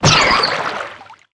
Sfx_creature_penguin_flinch_sea_02.ogg